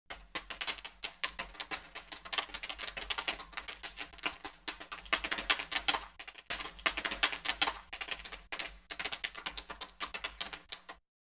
Звуки попкорна
Звук жарящегося попкорна — вариант 4